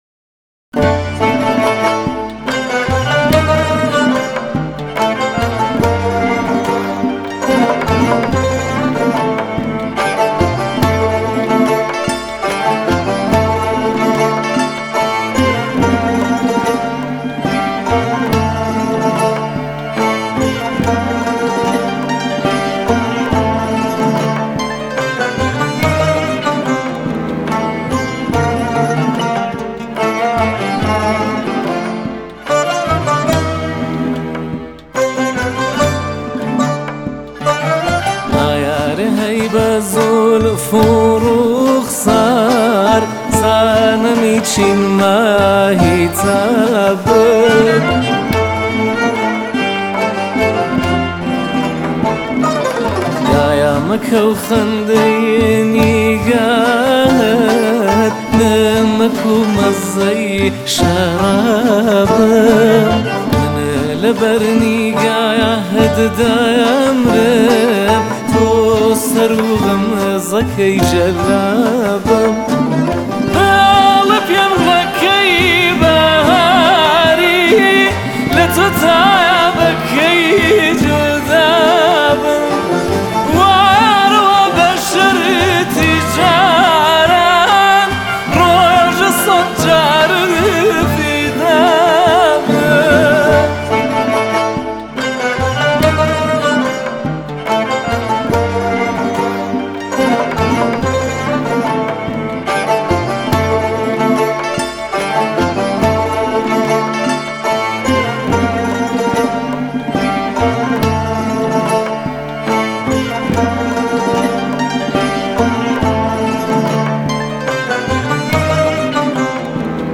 آهنگ کردی جدید